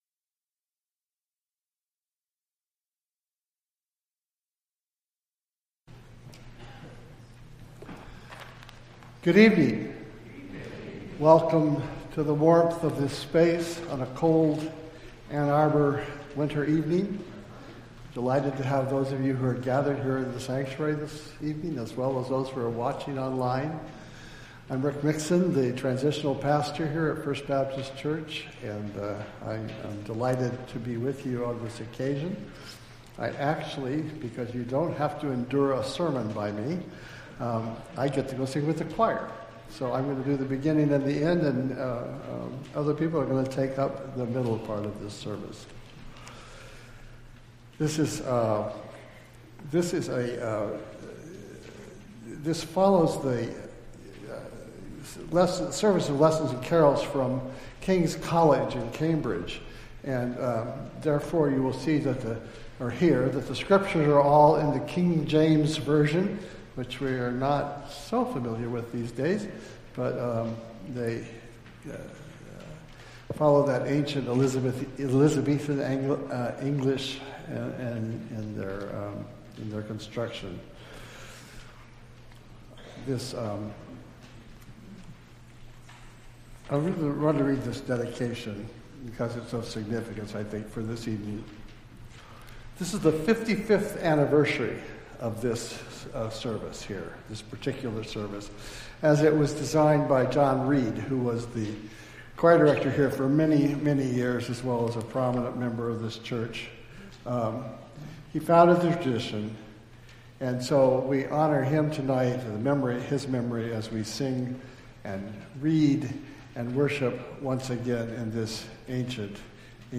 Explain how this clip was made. Entire December 24th Service